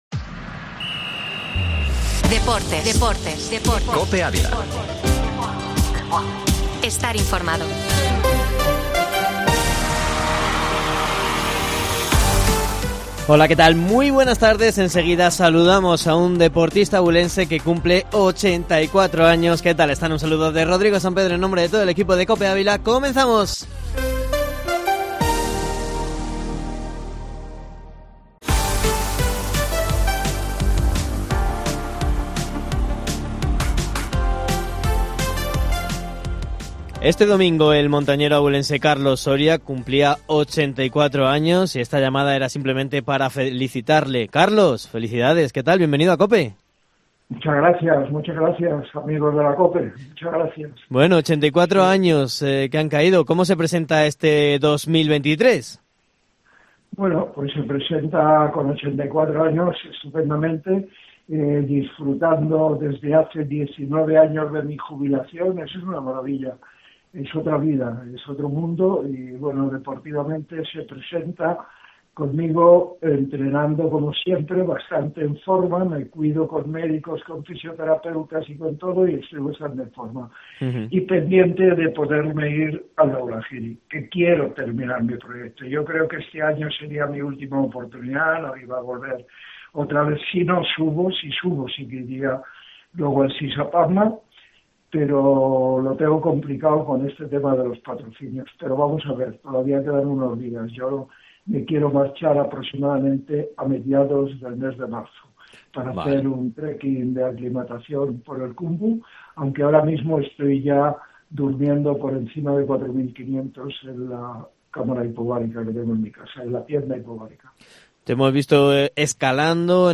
ENTREVISTA Deportes-7-feb-Carlos-Soria